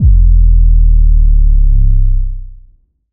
• phonk kicks oneshot 3 - 808 E.wav
Specially designed for phonk type beats, these nasty, layered 808 one shots are just what you need, can also help designing Hip Hop, Trap, Pop, Future Bass or EDM. Enjoy these fat, disrespectful 808 ...
phonk_kicks_oneshot_3_-_808_E_Oip.wav